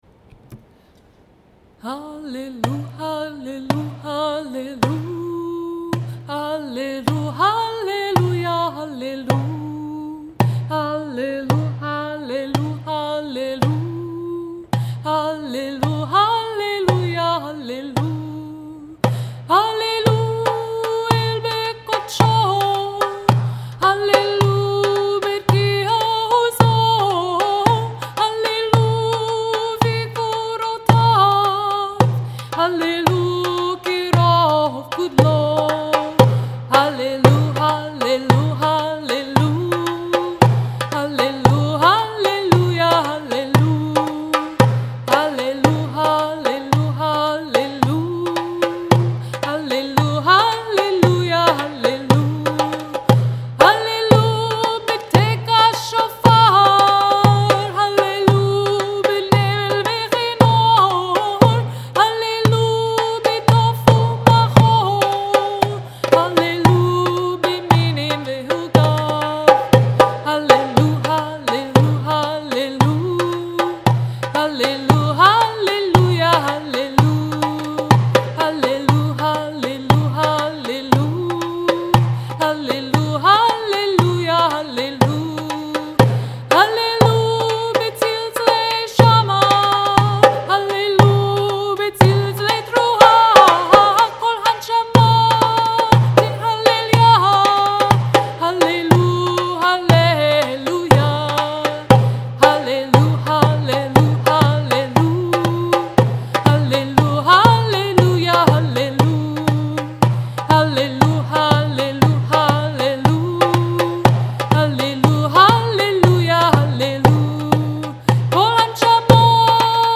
Kol Haneshama (Psalm 150), Sufi tune, with dumbek (recorded 2016).
sufi-psalm-150-with-dumbek.mp3